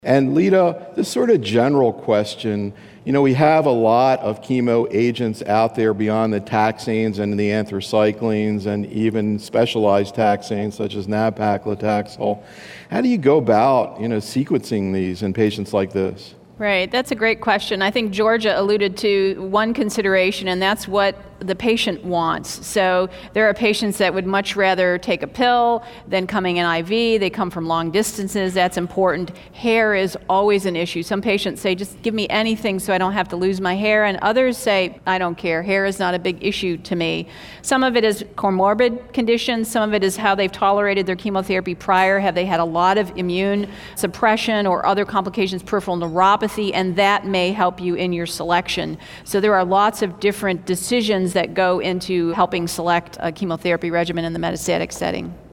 In these audio proceedings from a symposium held in conjunction with the Oncology Nursing Society's 2012 Annual Congress, the invited oncology nursing professionals participating as part of our faculty panel present actual patient cases from their practices, setting the stage for faculty discussion of optimal therapeutic and supportive care strategies in breast cancer.